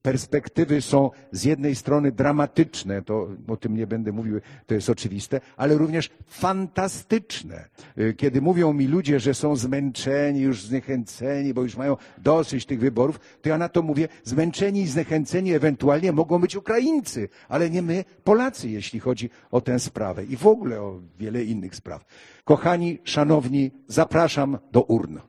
Do głosowania w wyborach zachęcał dyrektor Teatru Polskiego w Warszawie – Andrzej Seweryn: